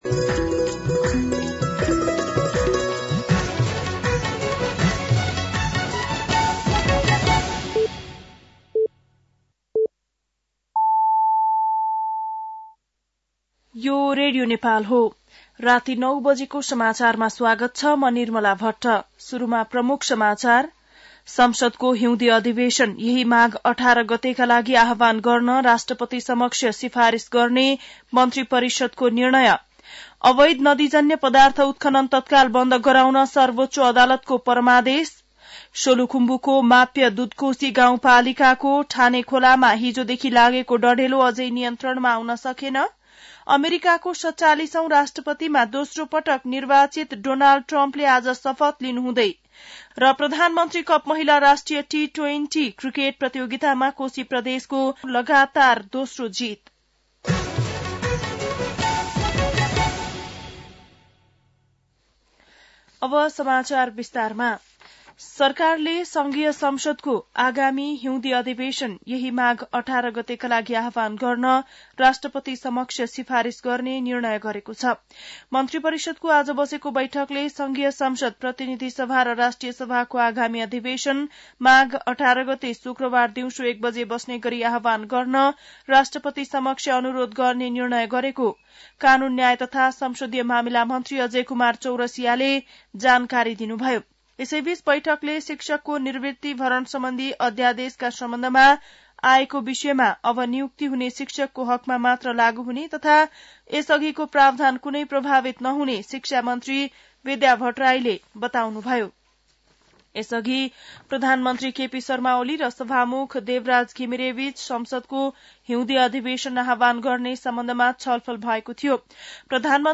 बेलुकी ९ बजेको नेपाली समाचार : ८ माघ , २०८१
9-PM-Nepal-NEWS-10-07.mp3